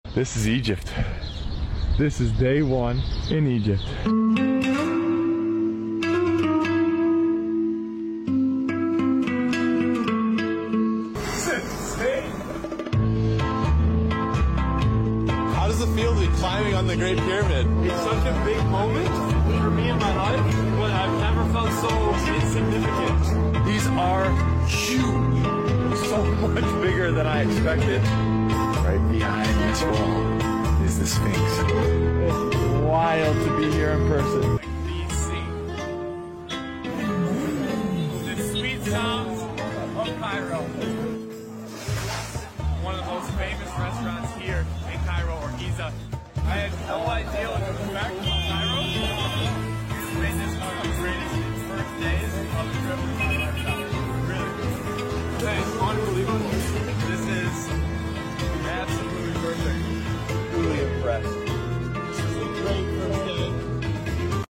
امريكى بيوصف اول يوم في sound effects free download